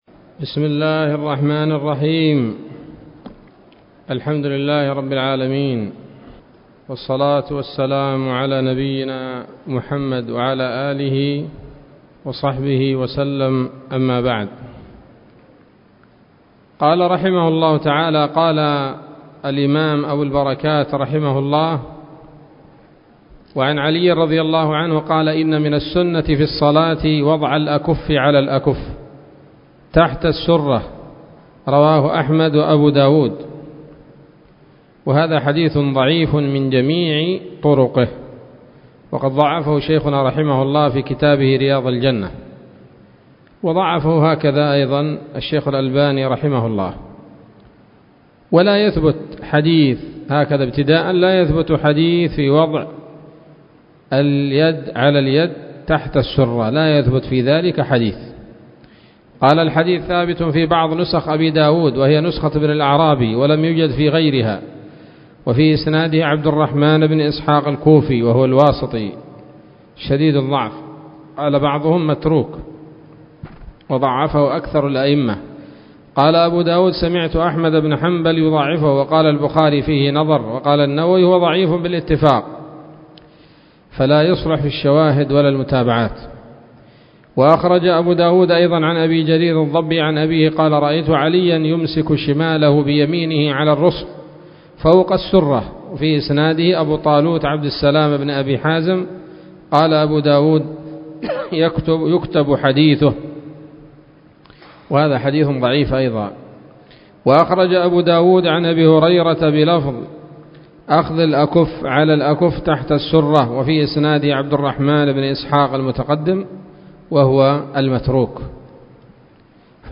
الدرس الثالث عشر من أبواب صفة الصلاة من نيل الأوطار